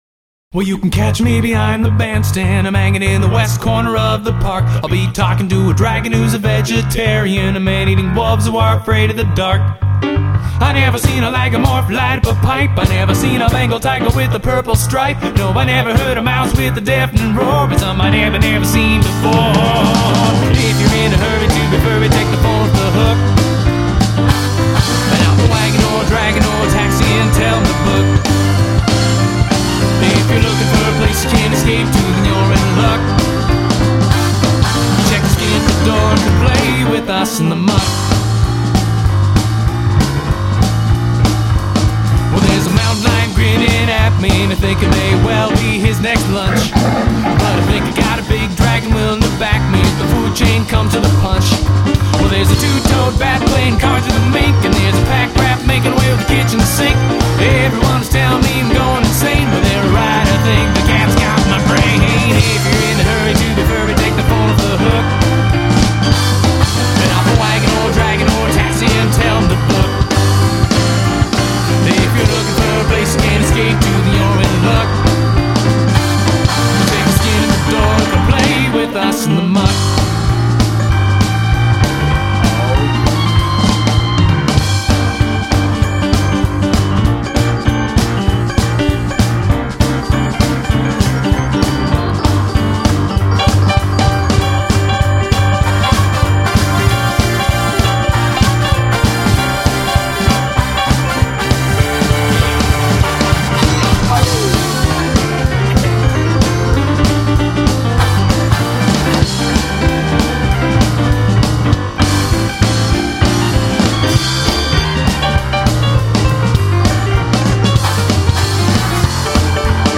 It was an upbeat jazzy number with this chorus: